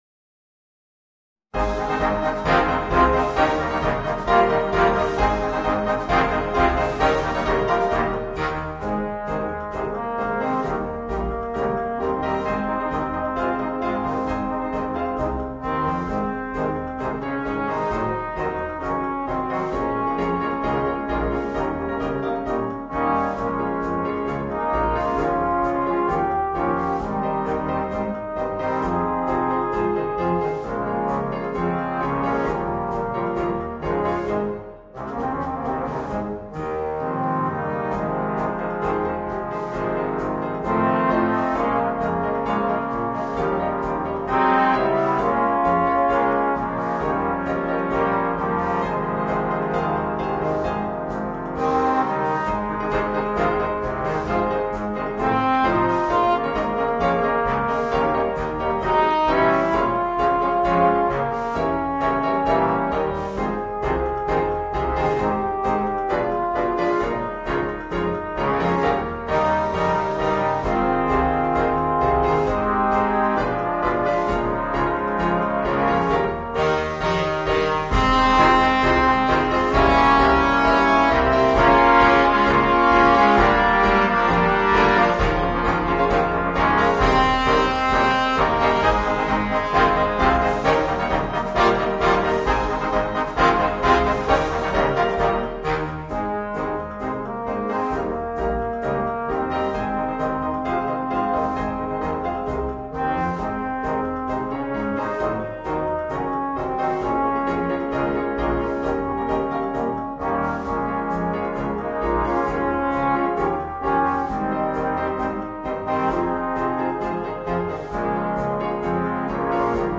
Voicing: 5 Trombones